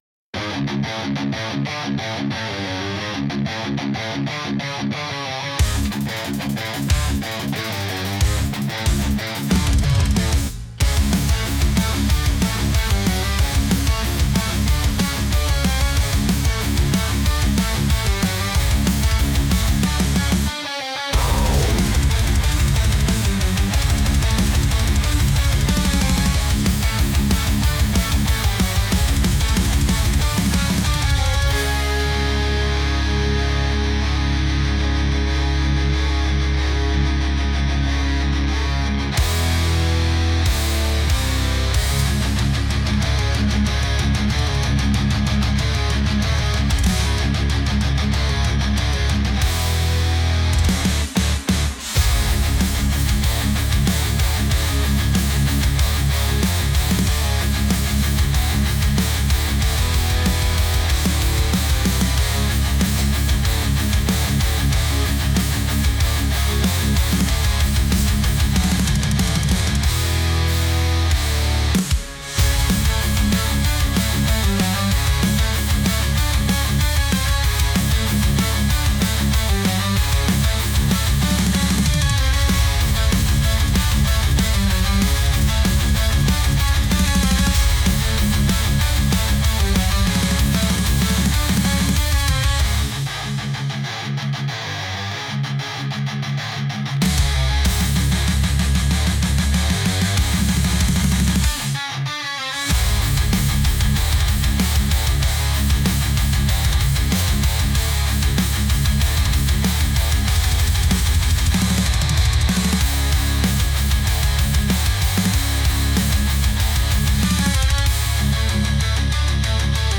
バトル勃発BGMです。